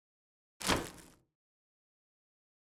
Системные звуки Apple iMac и MacBook Pro и Air в mp3 формате
3. Звук очистки корзины
imac-ochistka-korziny.mp3